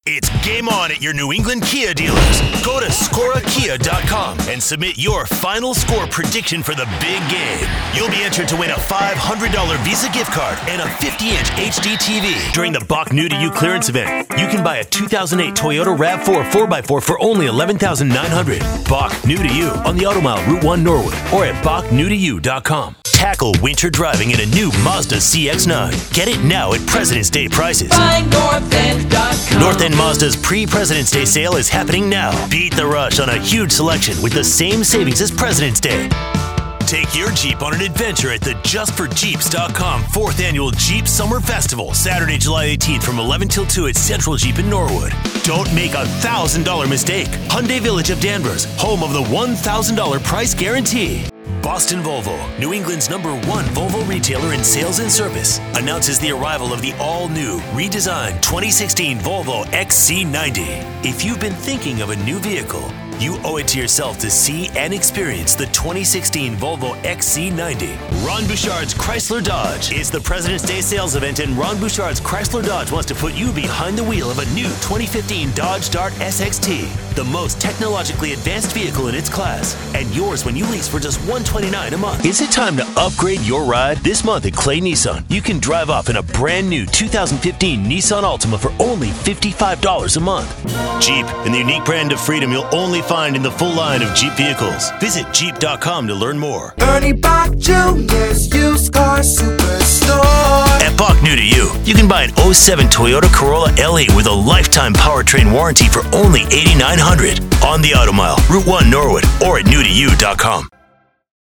Dynamic Voice Talent ✨ for all media